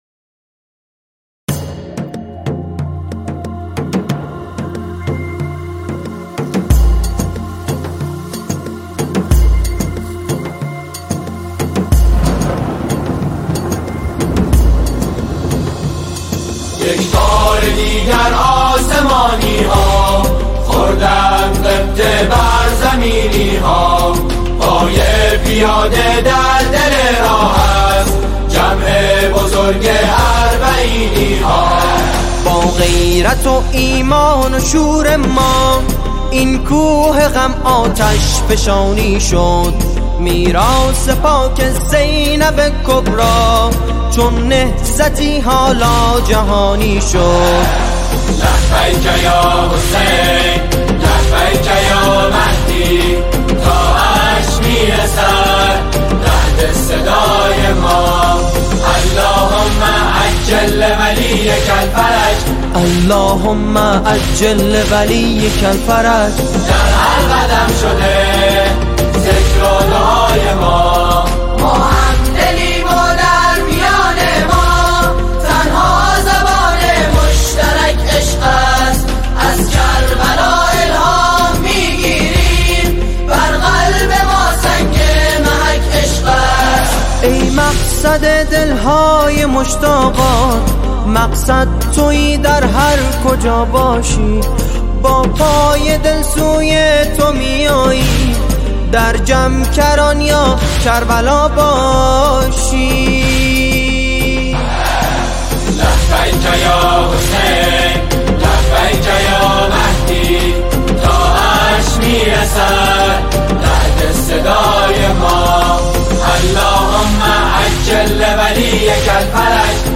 نماهنگ اربعین | «سرود اربعین» از گروه سرود مهدی یاوران اهواز